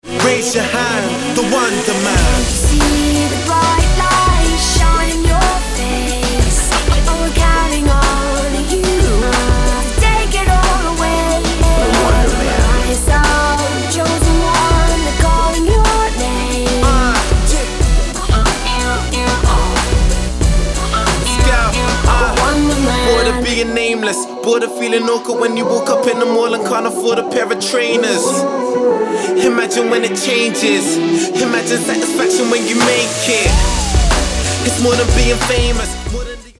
je londýnsky rapper narodený v Nigérii.